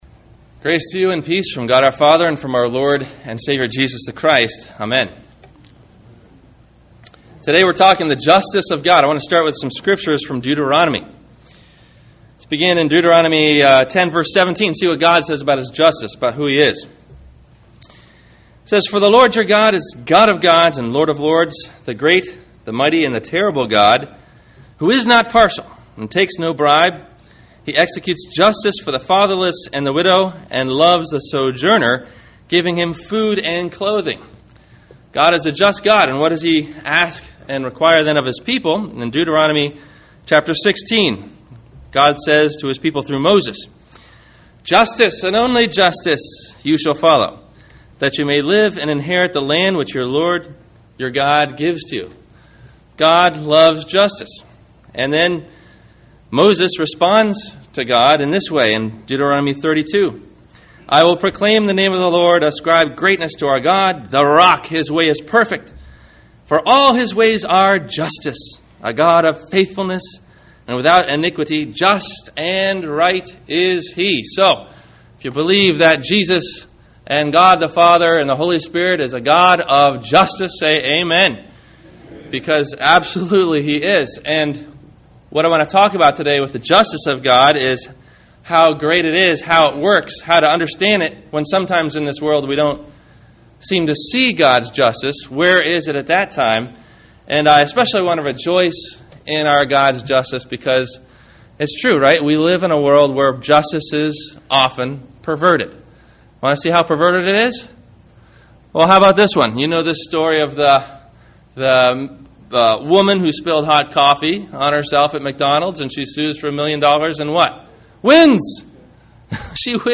Measure for Measure – Sermon – December 13 2009